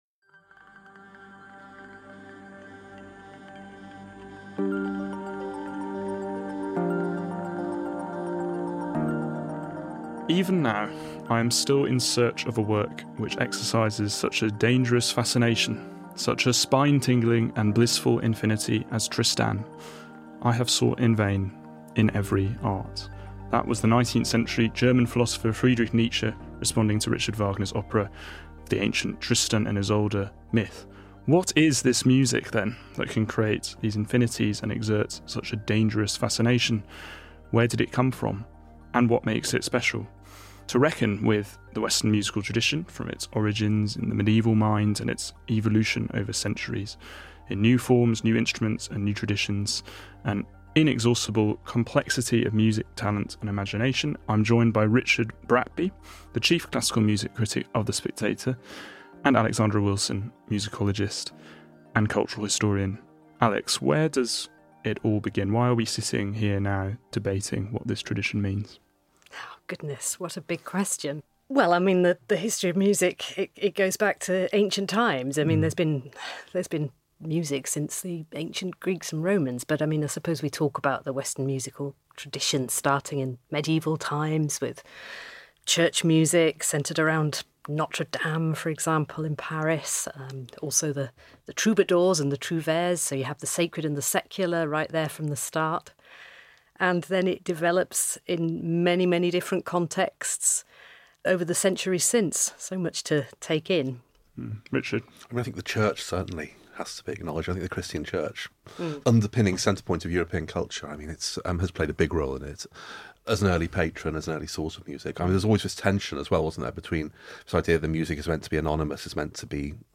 musicologist and cultural historian